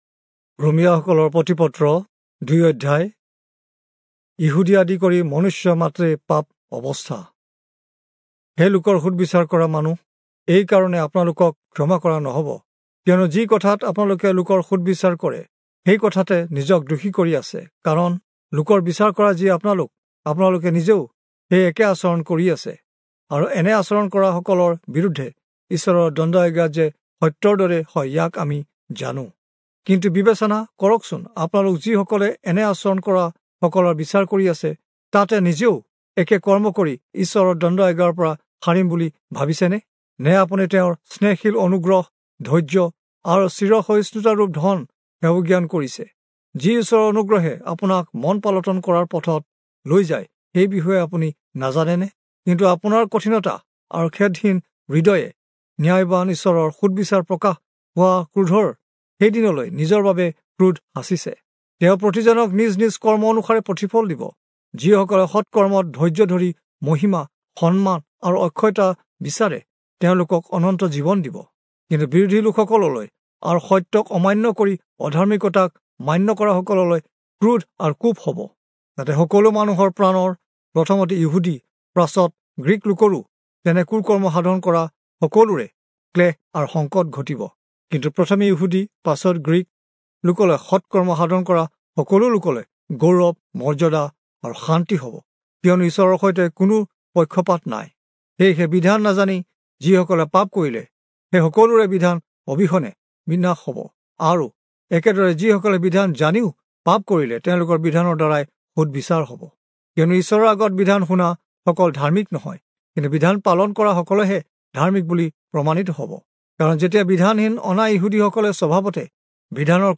Assamese Audio Bible - Romans 5 in Irvas bible version